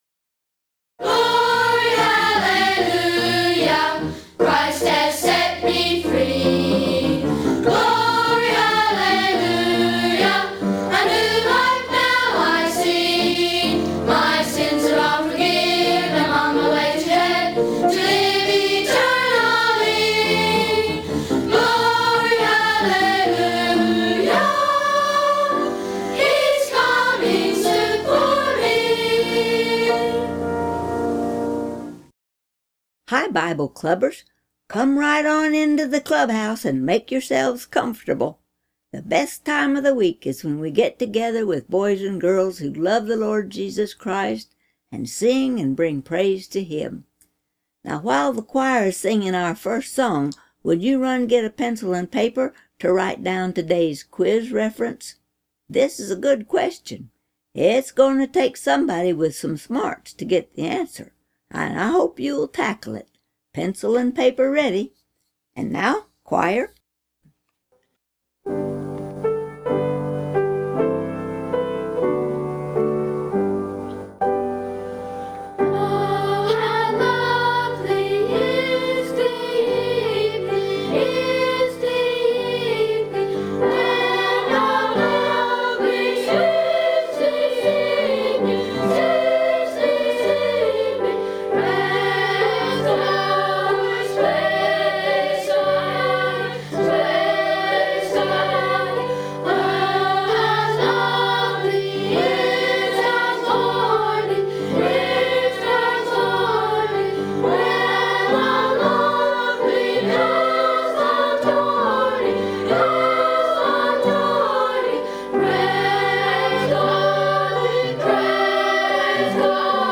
GRACE BIBLE CHURCH Audio Sermons